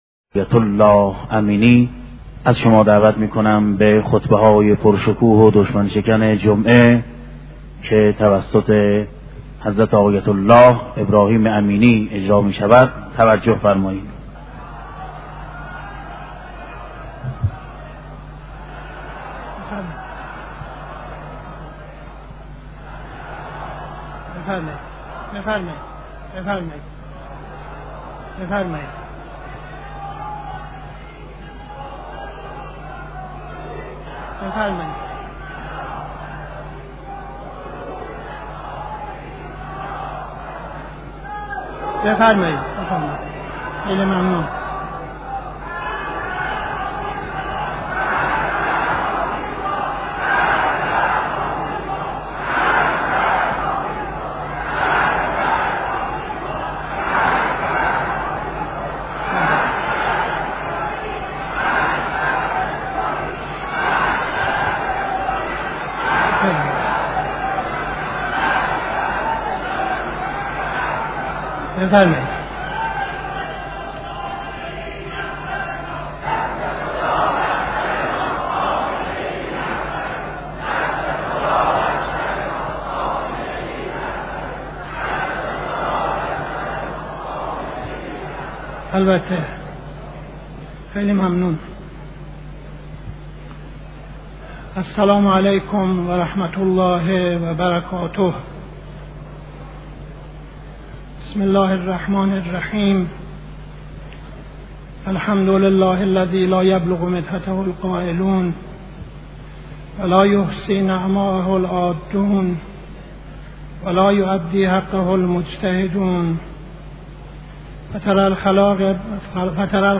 خطبه اول نماز جمعه 18-02-71